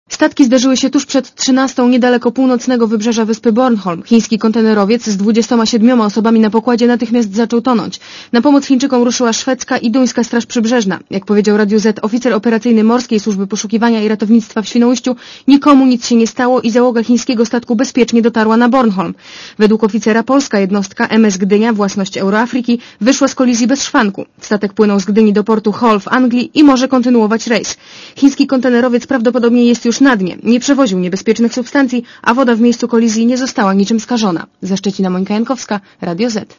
(RadioZet) Źródło: (RadioZet) Komentarz audio (145Kb) Na razie nie wiadomo dlaczego doszło do kolizji.